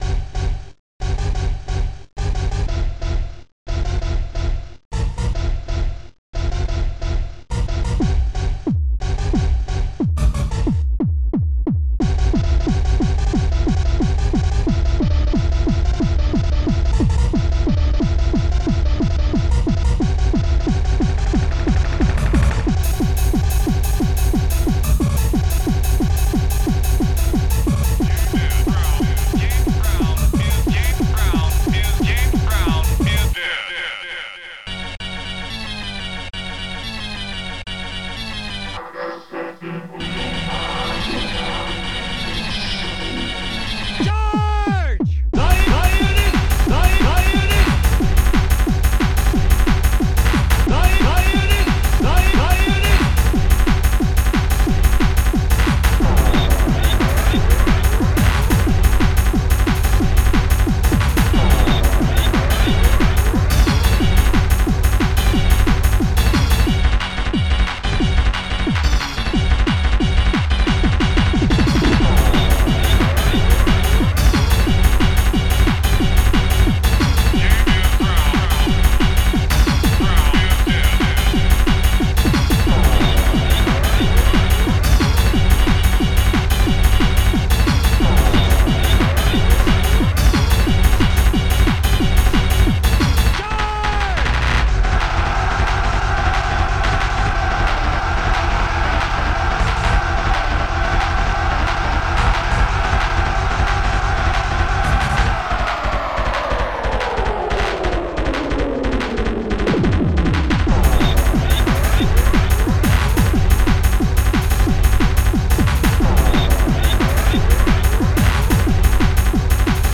xm (FastTracker 2 v1.04)